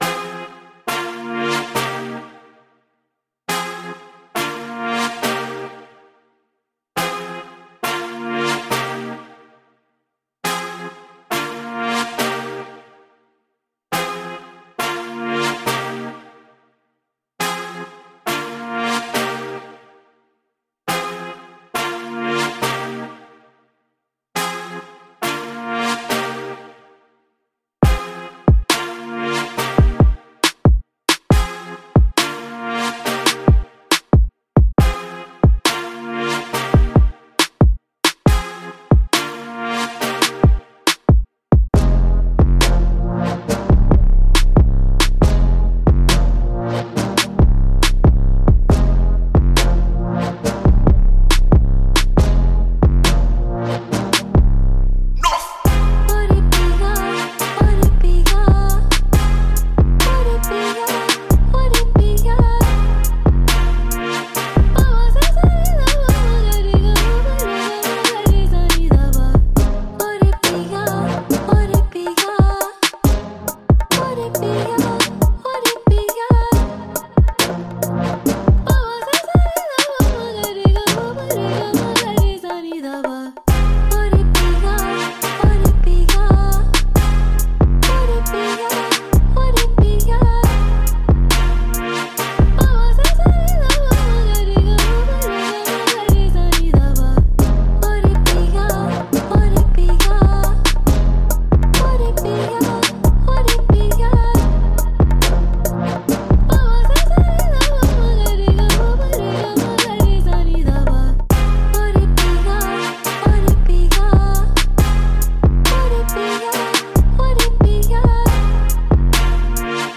intrumentals